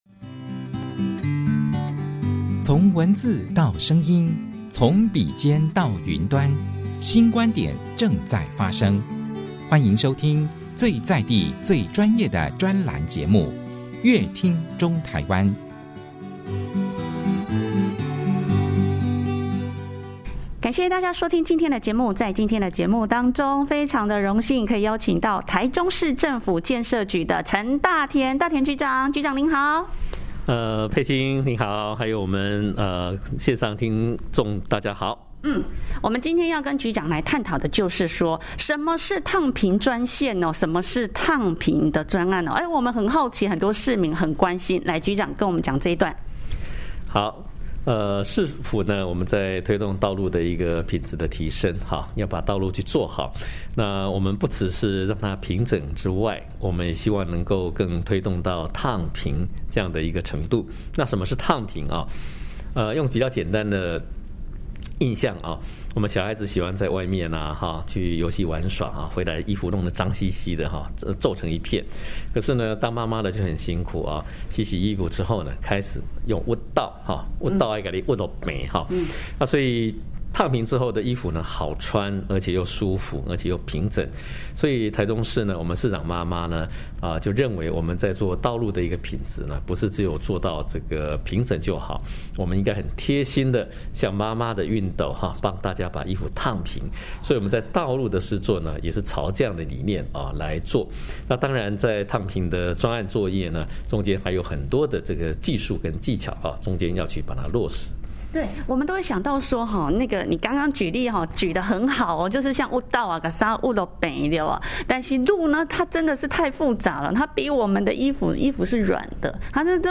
「人本、友善、無礙」燙平專案 媽媽市長盧秀燕注重台中道路品質，與建設局一同打造安全、平順的用路環境，道路平整對市民用路安全極重要，台中市政府建設局近年推動的「燙平專案」成果也獲民眾認同，而甚麼是燙平專案呢？陳局長在專訪中鉅細靡遺為市民朋友說明，建設局長說，建設局堅持用最嚴格的標準與最高品質要求，才能如同熨斗一般，燙出一條條讓市民朋友安心好走的道路。